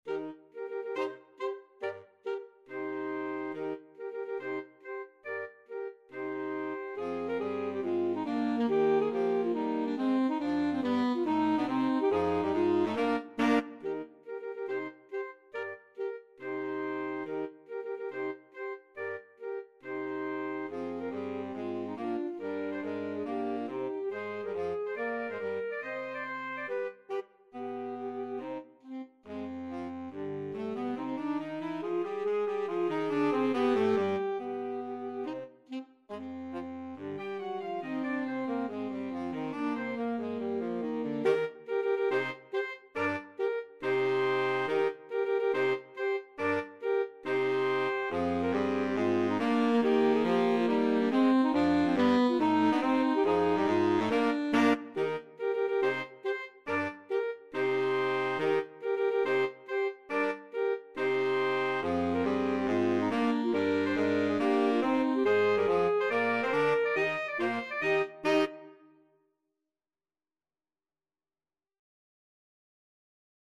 Free Sheet music for Saxophone Quartet
Alto Saxophone 1Alto Saxophone 2Tenor SaxophoneBaritone Saxophone
Eb major (Sounding Pitch) (View more Eb major Music for Saxophone Quartet )
4/4 (View more 4/4 Music)
Tempo di marcia =140
Classical (View more Classical Saxophone Quartet Music)